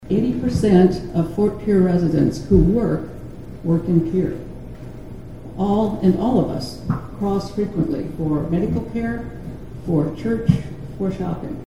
Various local, state and federal dignitaries gathered on Tuesday (March 25, 2025) to hold the official ribbon cutting ceremony for the new Lieutenant Commander John C. Waldron Memorial Bridge over the Missouri River between Fort Pierre and Pierre.